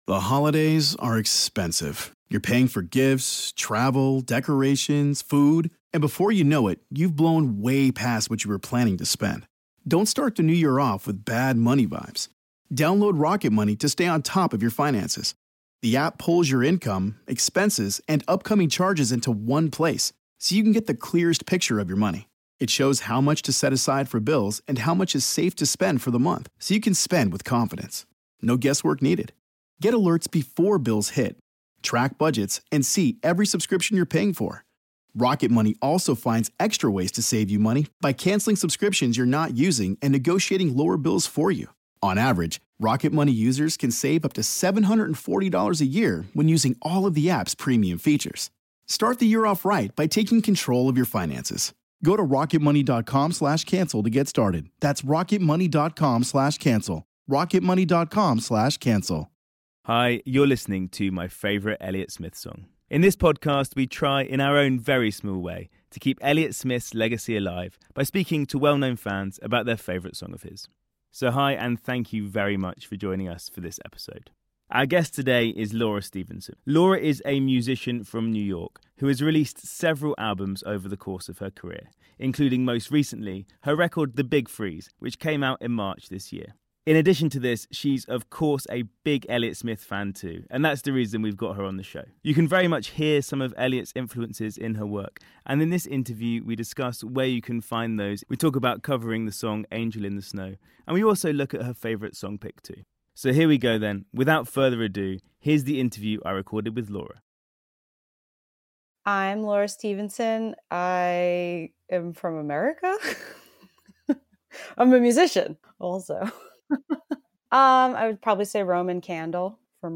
In this interview we talk about how those influences come up in her music, her covering the song Angel in the Snow and her pick for her favourite song too.